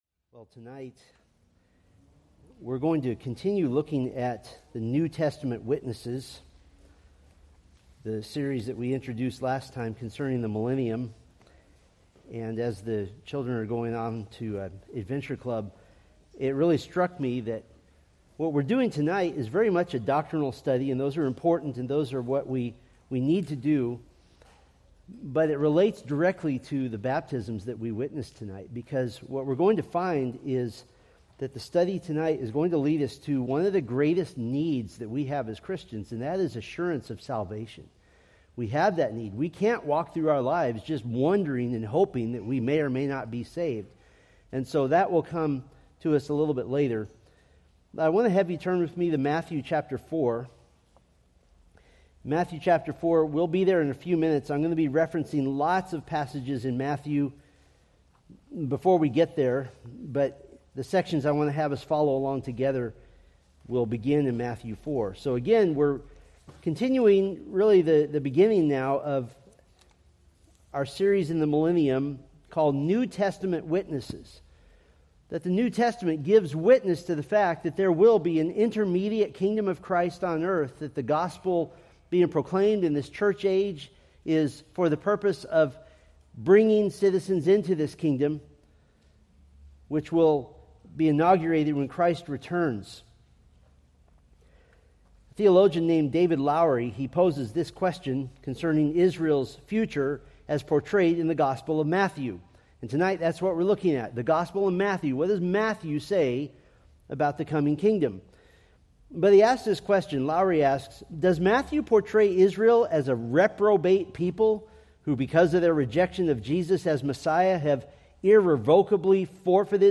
From the Millennium: New Testament Witnesses sermon series.